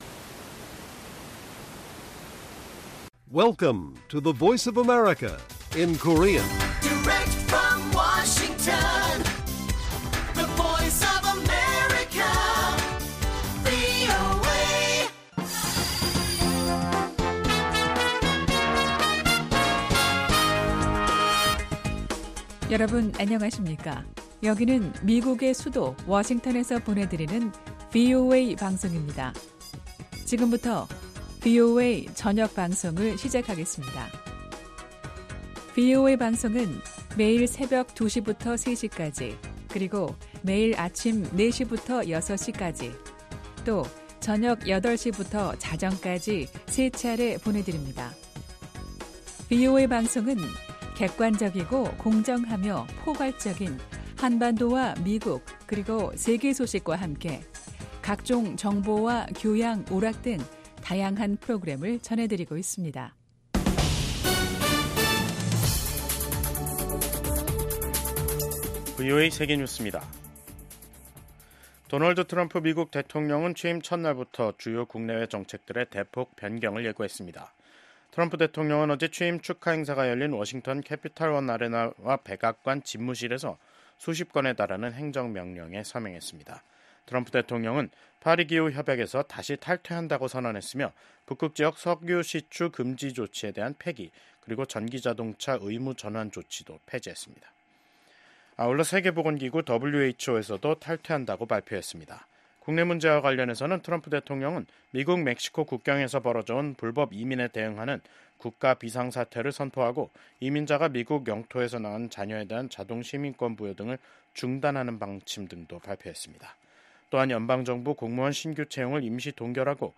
VOA 한국어 간판 뉴스 프로그램 '뉴스 투데이', 2025년 1월 21일 1부 방송입니다. 도널드 트럼프 미국 대통령이 4년 만에 백악관에 복귀했습니다. 취임 첫날 트럼프 대통령은 북한을 ‘핵보유국’이라고 지칭해 눈길을 끌었습니다.